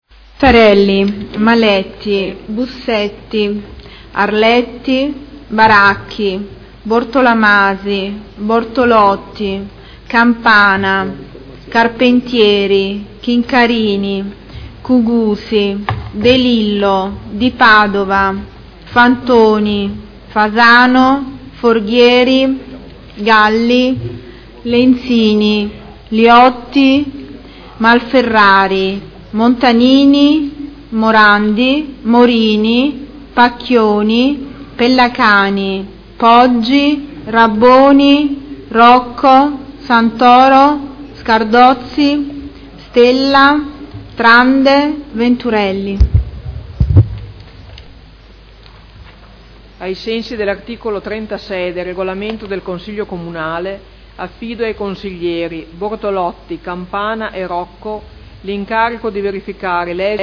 Segretario Generale — Sito Audio Consiglio Comunale
Seduta del 3 marzo. Appello